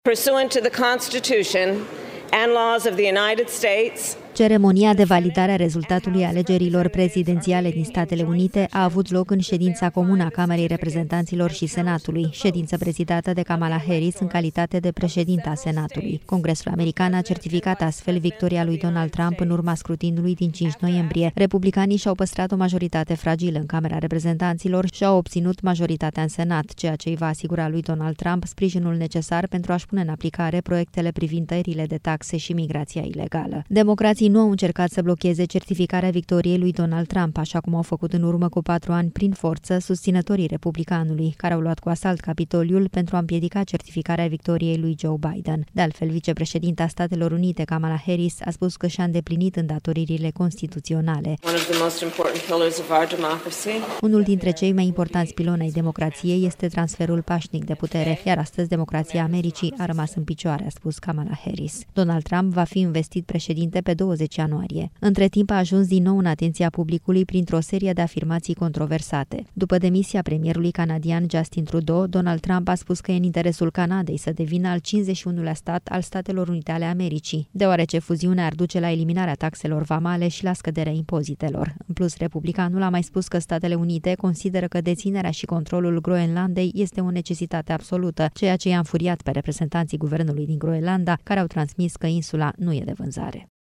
Kamala Harris, vicepreședinta SUA: „Astăzi democrația Americii a rămas în picioare”